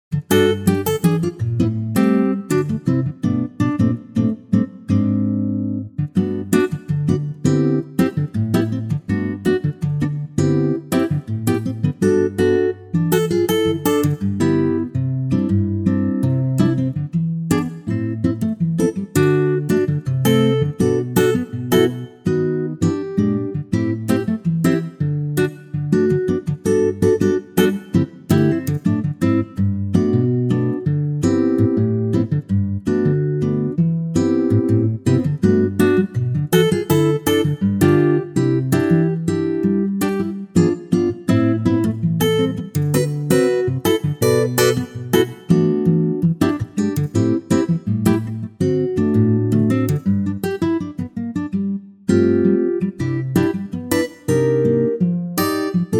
4 bar intro and vocal in at 4 seconds. vocal through
key - G - vocal range - G to Bb (optional B)
Superb acoustic guitar arrangement